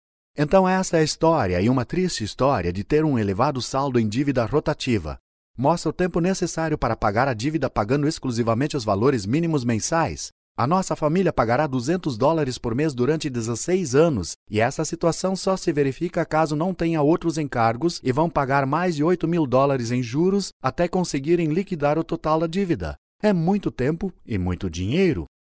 Male
E-learning
Mic Rode NT1A, soundboard Delta Audiophile 192, computer Dell
BaritoneBassVery Low
TrustworthyConversationalWarmFriendlyRealHappySexyAuthoritativeCalmStrongHoarseDarkNeutralUpbeatCharming